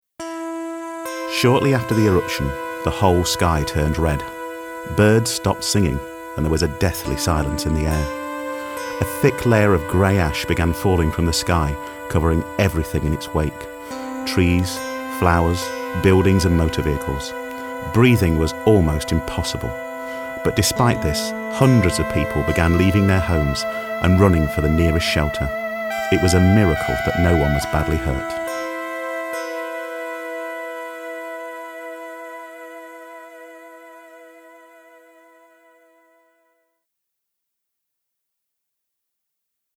Volcano documentary